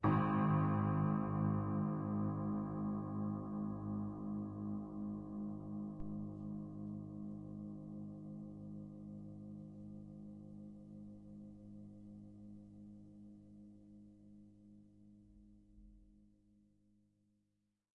描述：记录了一架GerardAdam钢琴，它至少有50年没有被调音了！
Tag: 失谐 恐怖 踏板 钢琴 弦乐 维持